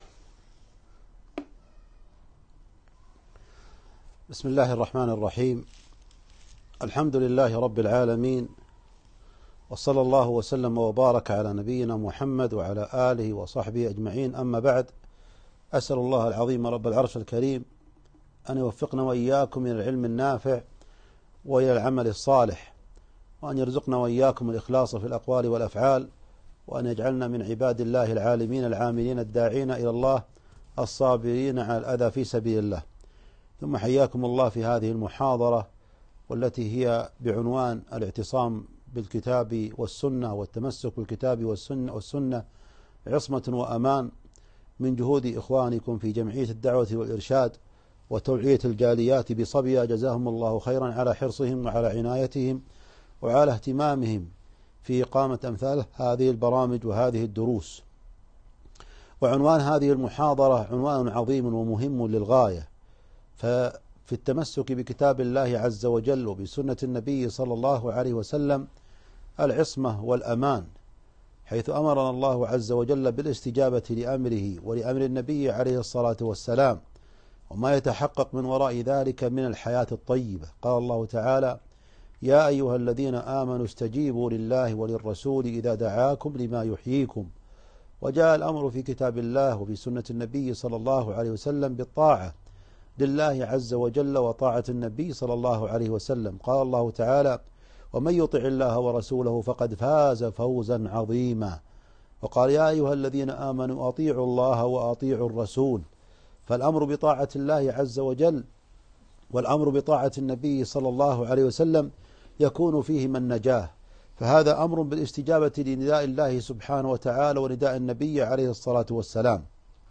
محاضرة - التمسك بالكتاب والسنة عصمة وأمان